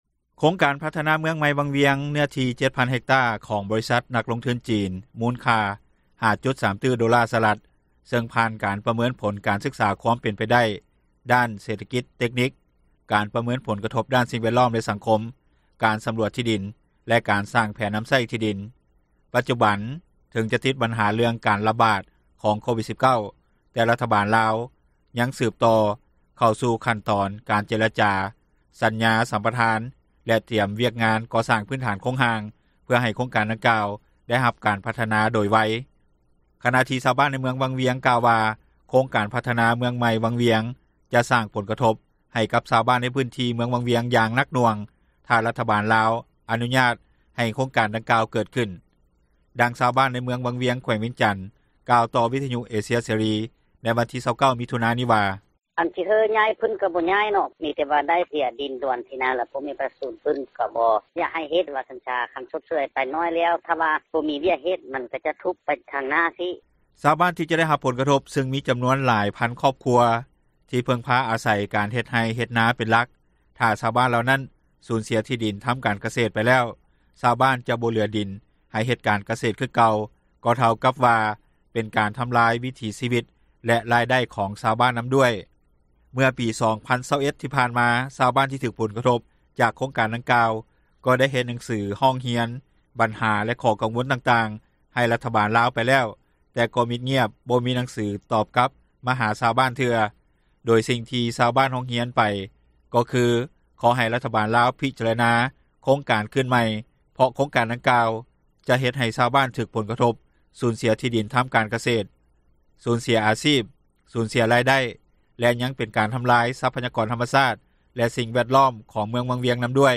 ດັ່ງຊາວບ້ານໃນວັງວຽງ ແຂວງວຽງຈັນ ກ່າວຕໍ່ວິທຍຸເອເຊັຽເສຣີ ໃນວັນທີ 29 ມິຖຸນານີ້ວ່າ:
ດັ່ງຊາວບ້ານ ໃນເມືອງວັງວຽງ ແຂວງວຽງຈັນ ອີກທ່ານນຶ່ງ ໄດ້ກ່າວຕໍ່ວິທຍຸເອເຊັຽເສຣີ ໃນວັນທີ 29 ມິຖຸນາ ນີ້ວ່າ:
ດັ່ງນັກວິຊາການ ດ້ານເສຖກິຈ-ສັງຄົມ ແລະອາຈາຈາກ ມຫາວິທຍາລັຍແຫ່ງຊາດລາວ ກ່າວຕໍ່ວິທຍຸເອເຊັຽເສຣີ ໃນວັນທີ 29 ມິຖຸນານີ້ວ່າ: